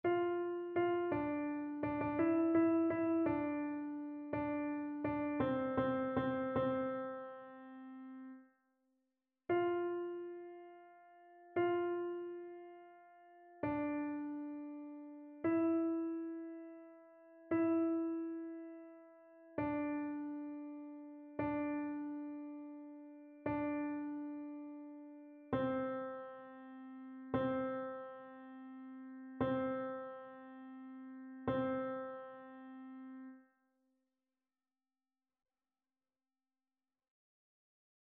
Alto
annee-b-temps-pascal-5e-dimanche-psaume-21-alto.mp3